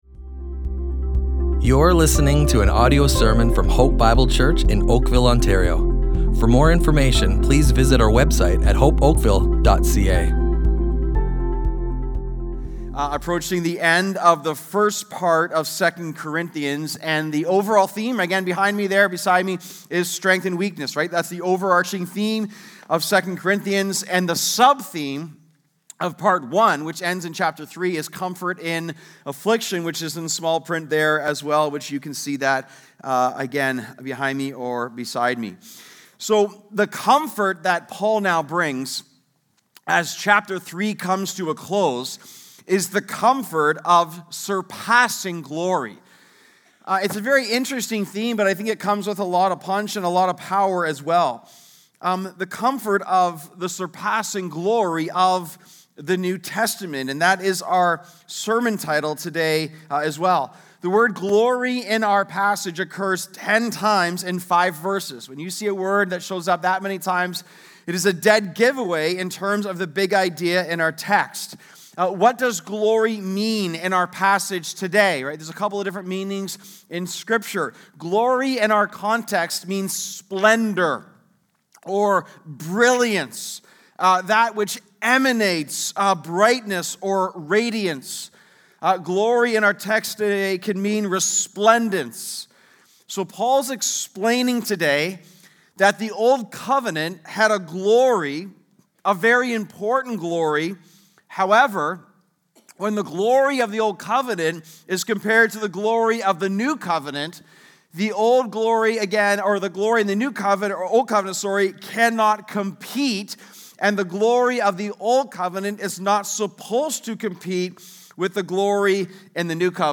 Audio Sermons